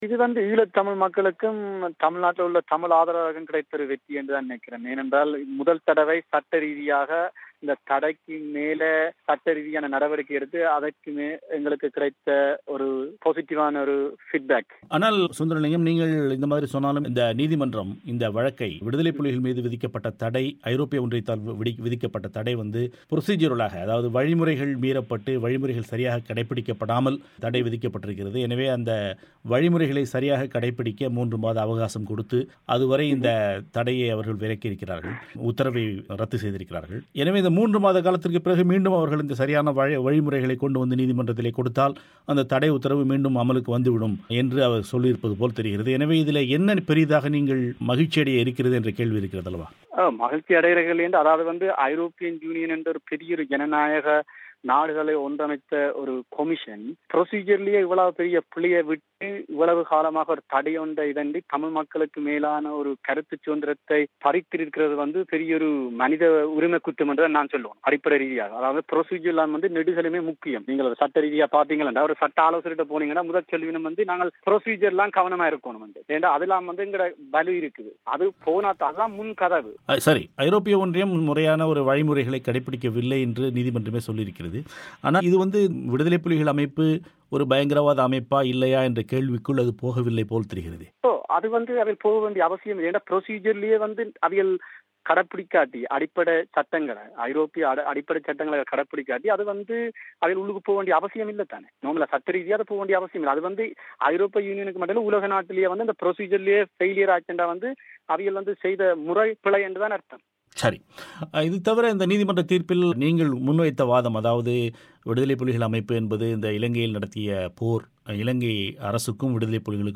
அவர் தமிழோசைக்கு வழங்கிய செவ்வியை நேயர்கள் இங்கு கேட்கலாம்.